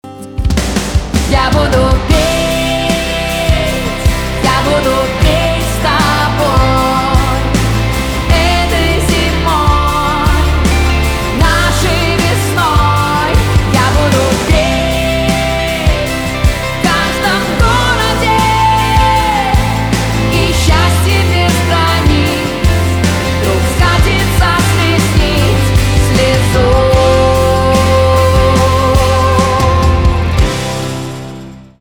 русский рок
гитара , барабаны